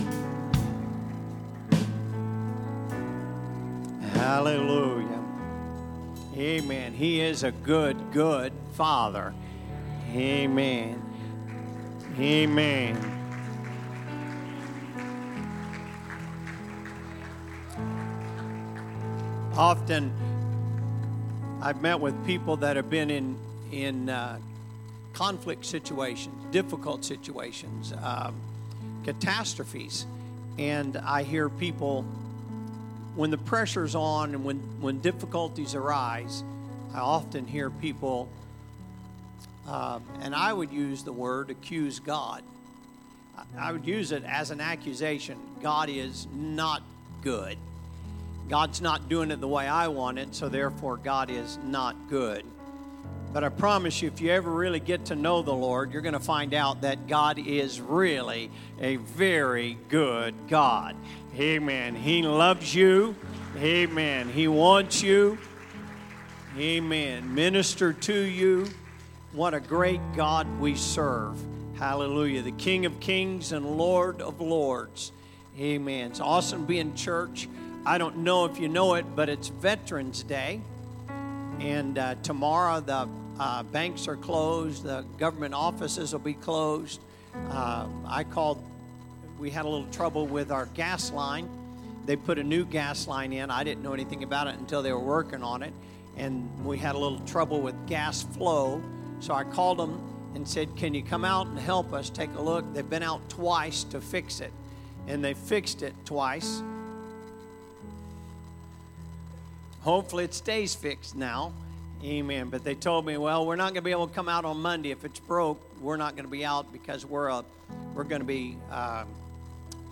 Sunday Service - Part 17